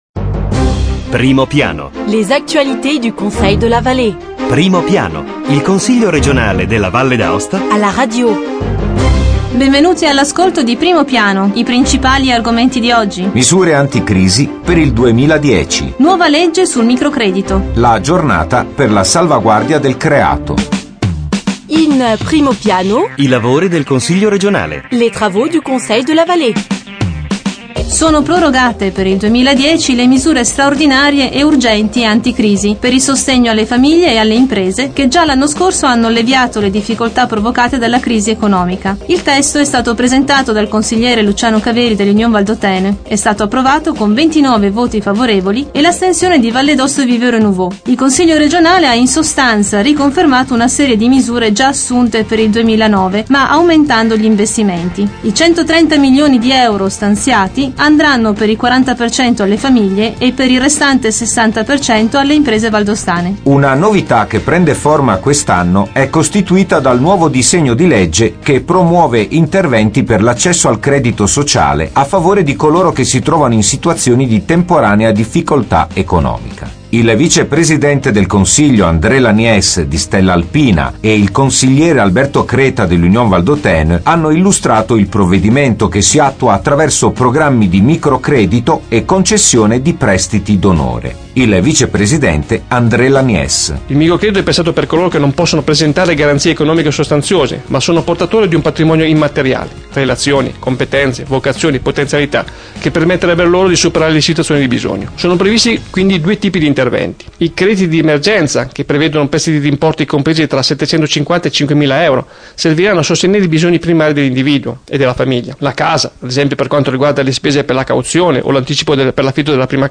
Eventi e ricorrenze Documenti allegati Dal 19 gennaio 2010 al 25 gennaio 2010 Primo Piano Il Consiglio regionale alla radio: approfondimento settimanale sull'attivit� politica, istituzionale e culturale dell'Assemblea legislativa. Questi gli argomenti del nuovo appuntamento con Primo Piano: I lavori del Consiglio Valle: misure straordinarie anticrisi per il 2010 e nuova legge sul microdredito. Interviste con il Vicepresidente del Consiglio Valle, Andr� Lani�ce, e con il Consigliere Gianni Rigo.